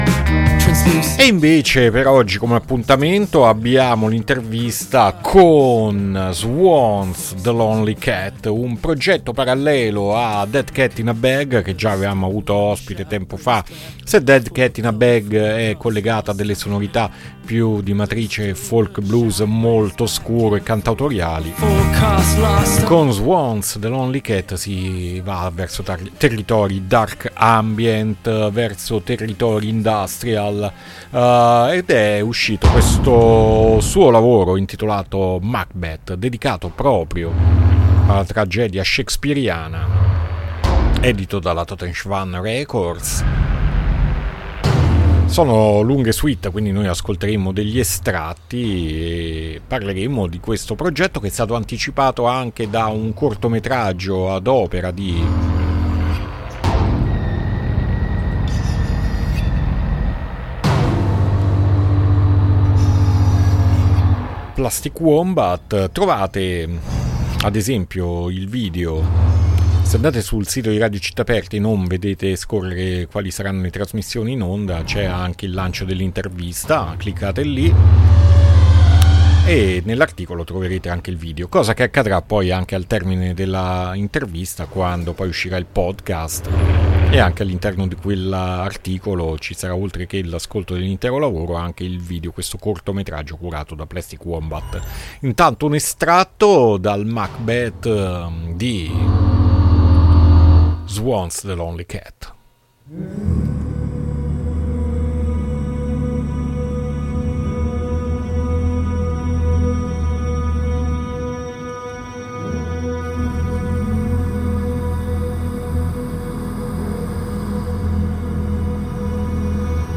INTERVISTA SWANZ THE LONELY CAT A PUZZLE 5-2-2024